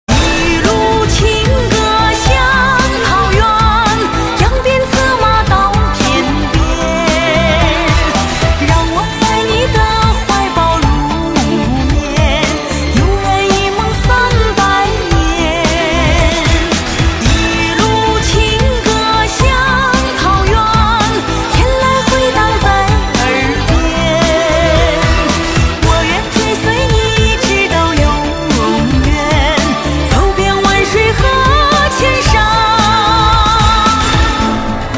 M4R铃声, MP3铃声, 华语歌曲 77 首发日期：2018-05-15 13:14 星期二